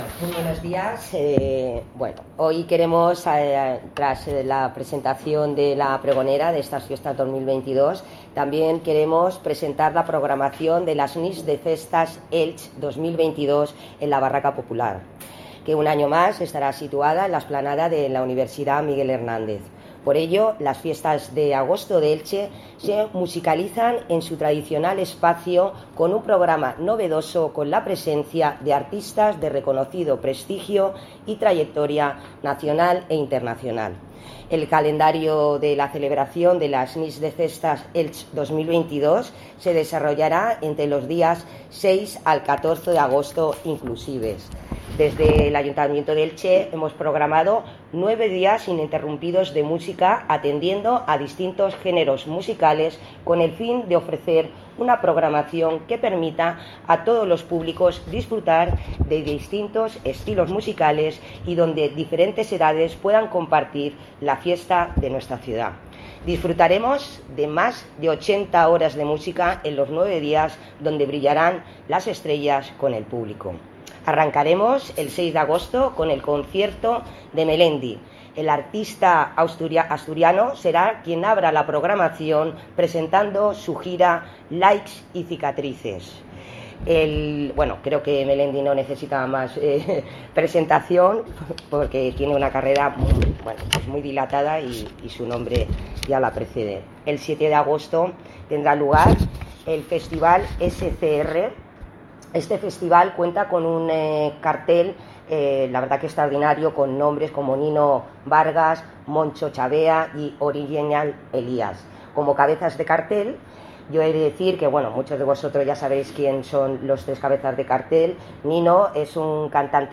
AUDIO concejala de fiestas, Mariola Galiana.: